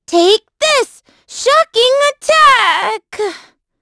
Estelle-Vox_Skill1_b.wav